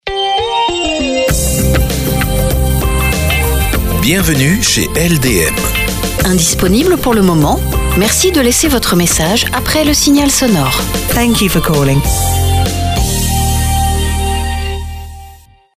Message répondeur professionnel